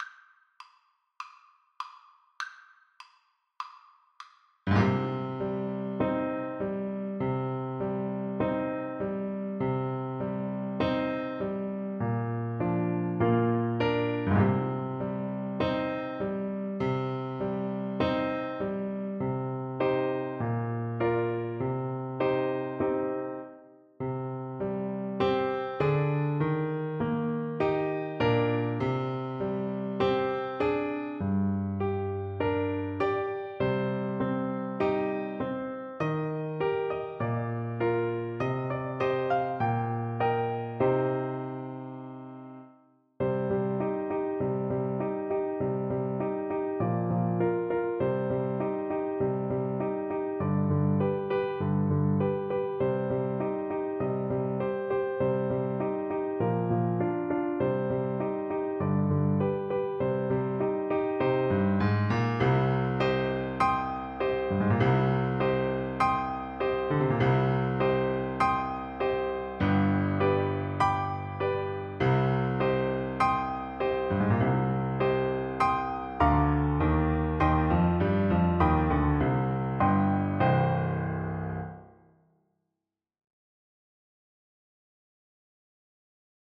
Traditional Trad. Pretty Little Dog Trumpet version
Trumpet
4/4 (View more 4/4 Music)
C minor (Sounding Pitch) D minor (Trumpet in Bb) (View more C minor Music for Trumpet )
Traditional (View more Traditional Trumpet Music)
pretty_little_dog_TPT_kar1.mp3